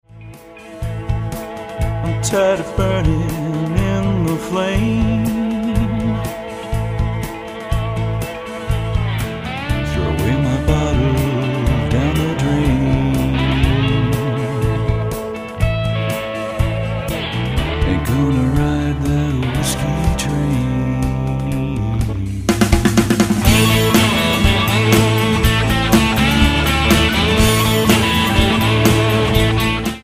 vocals, electric and acoustic guitars
bass guitar
drums, hand-held percussion
Epiphone EJ-200 acoustic guitar
'64 Gretsch Tennessean,
70s Japanese Les Paul Jr. copy (slide guitar)
'75 Fender Jazz bass
Yamaha drum kit, shaker
Recorded September 2003 at the Hangar, Sacramento, CA